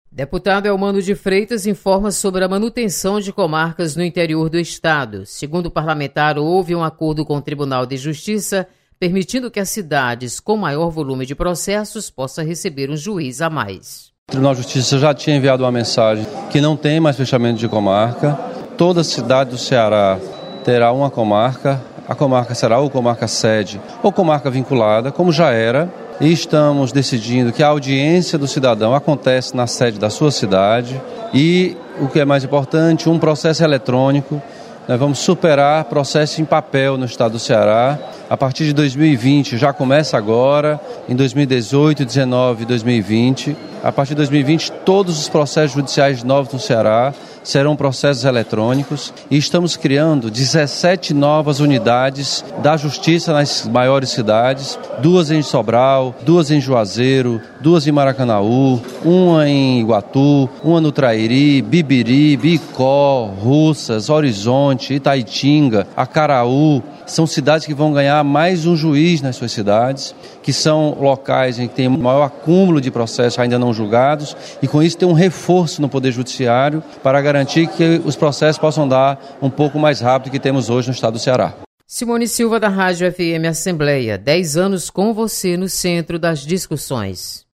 Você está aqui: Início Comunicação Rádio FM Assembleia Notícias Comarcas